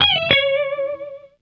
07 DiddlyDood C#.wav